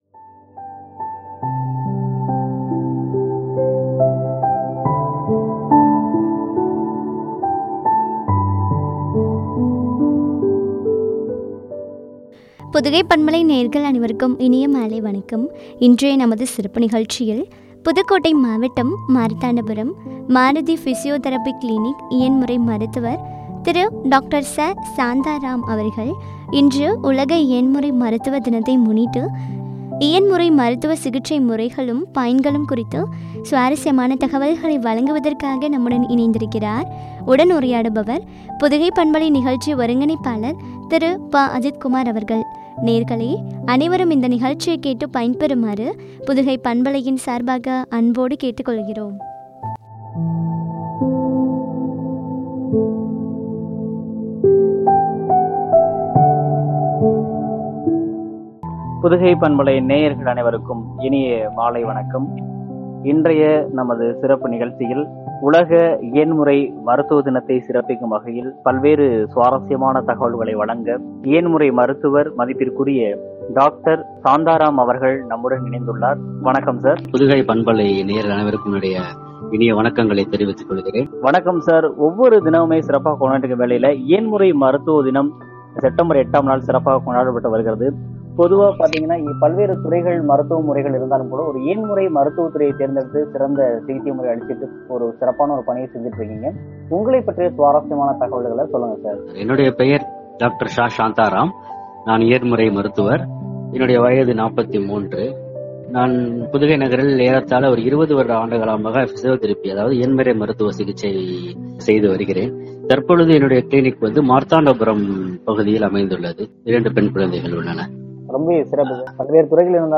பயன்களும் குறித்து வழங்கிய உரையாடல்.